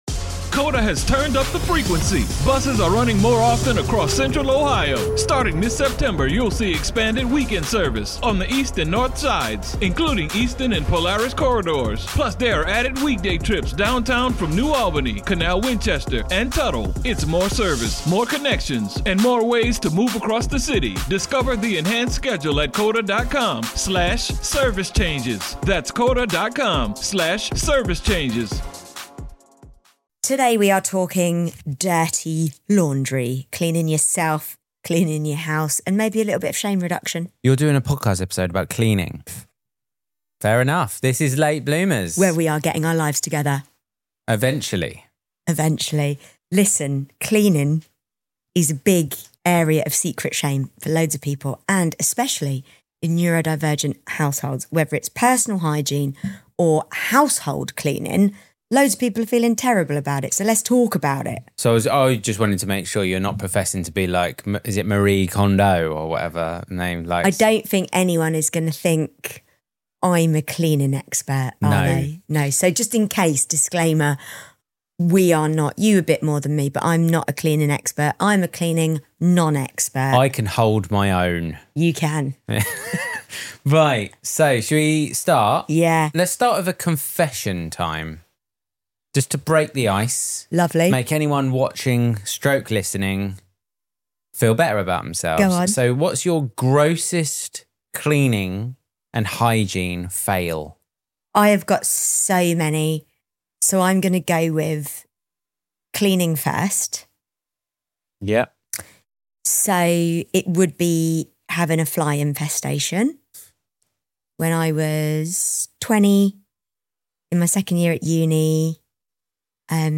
This is the shame-free conversation we all need: raw, funny, and practical.